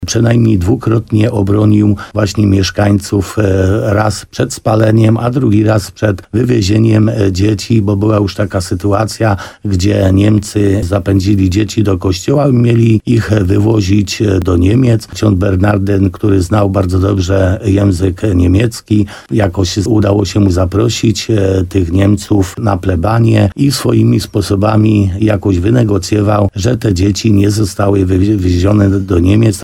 – To człowiek, który nie bał się Niemców i komunistów – mówi wójt gminy Laskowa Piotr Stach, który pamięta zmarłego w 1986 roku ks. Dziedziaka. Samorządowiec wspomina jedną z historii o kapłanie z czasów II wojny światowej.